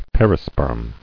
[per·i·sperm]